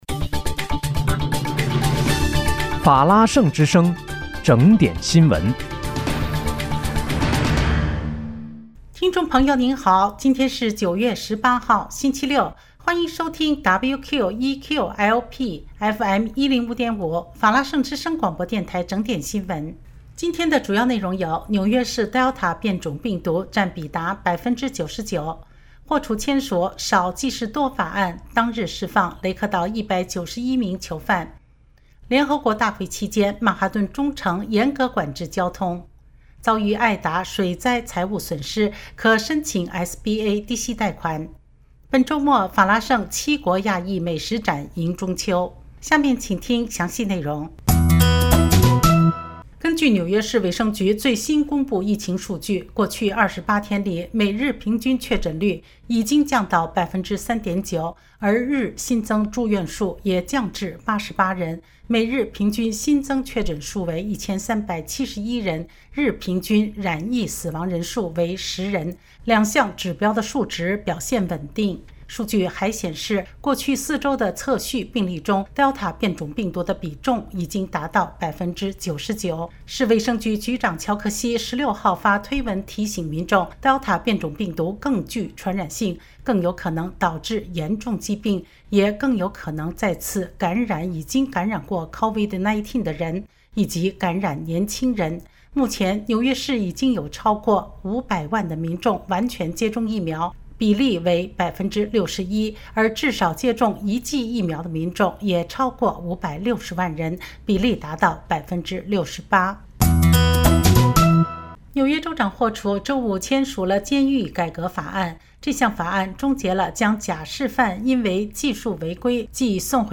9月18日（星期六）纽约整点新闻